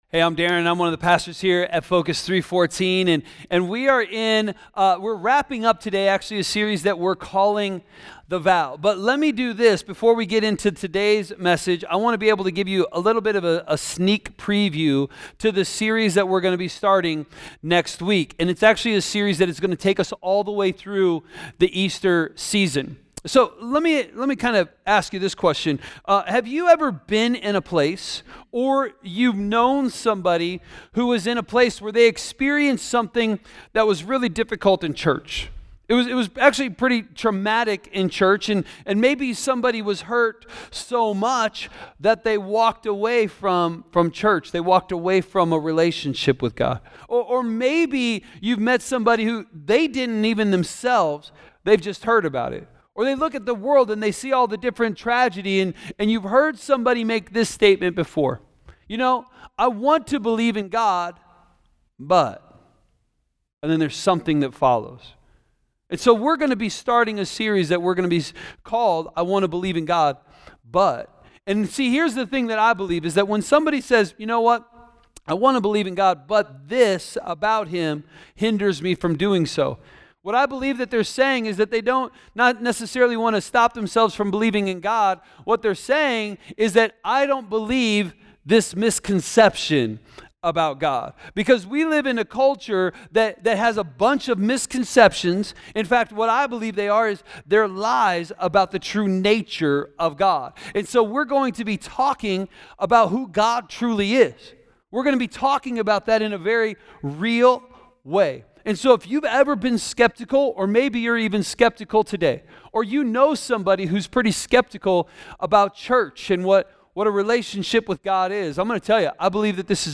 A message from the series "The Vow." Wedding vows are more than a declaration of love—they hold the keys to a strong, lasting marriage.